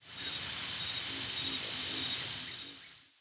Cape May Warbler nocturnal
presumed Cape May Warbler nocturnal flight calls